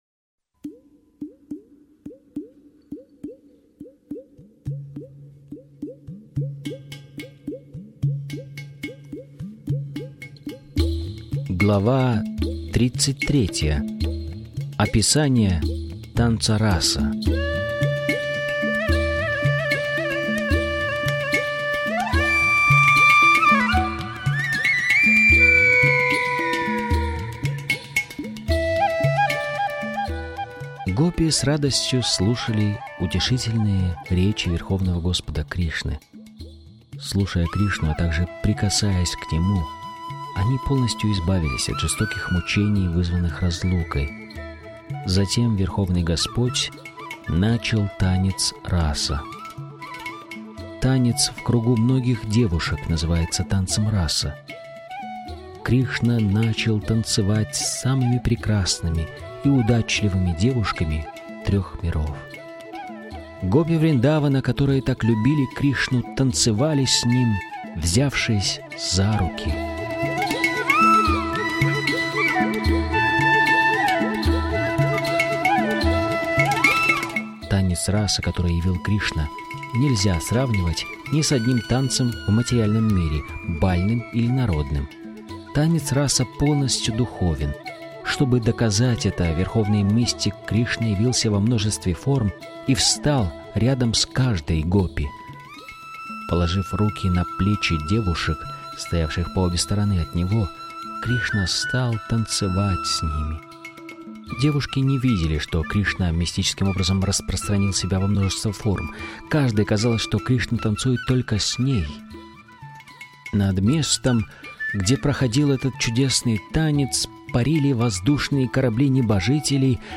Источник вечного наслаждения автор Абхай Чаран Де, Бхактиведанта Свами Прабхупада Информация о треке Автор аудиокниги : Абхай Чаран Де Бхактиведанта Свами Прабхупада Аудиокнига : Кришна.